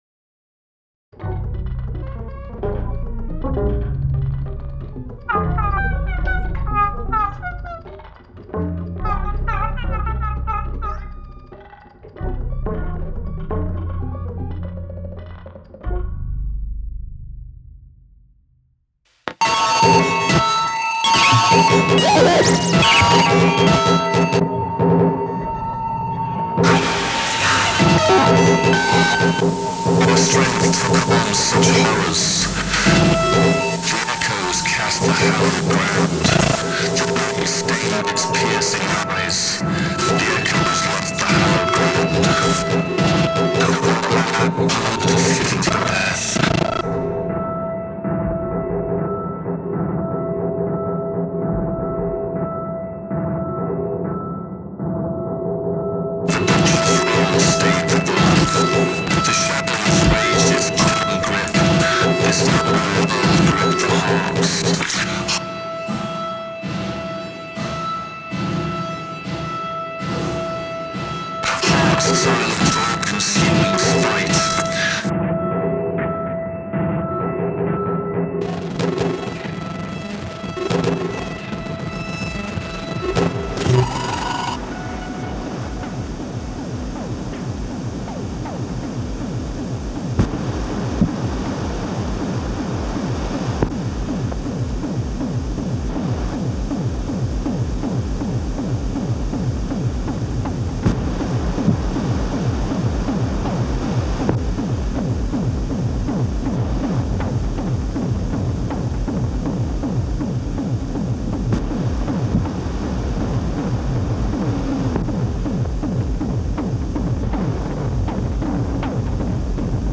To make a fair comparison, I’ve made several different renders of a section of my current composition, composed in 5th-order ambisonics.
Bear in mind that the mid-side render is not something that most people would use. It involves treating the first two channels of the 5th-order ambisonics signal as merely the mid and side channels of a two-channel virtual mid-side recording (omni plus figure-of-eight mics).
lusted-fleeting-demo8-mid-side.wav